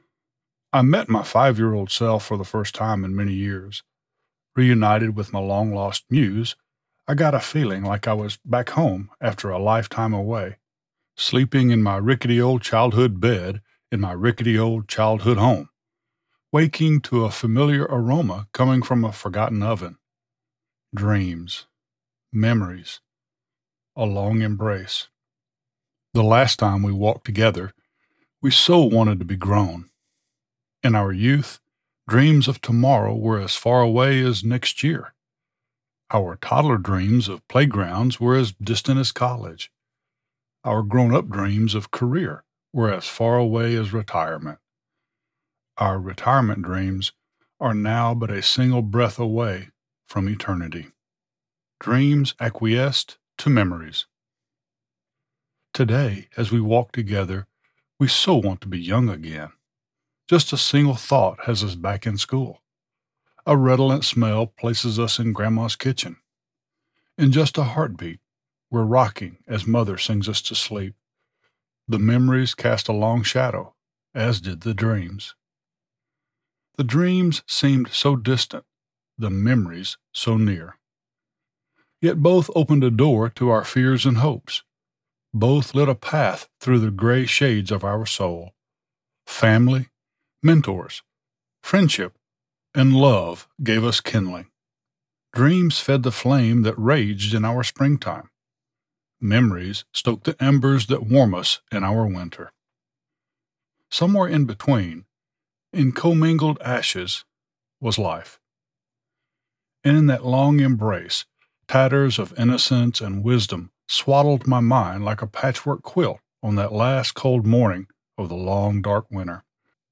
I am a professionally trained voice talent with a mature, slight southern accent.
Audiobook - 1st Person - Non-Fiction - Biography